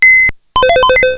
pc_turnon.wav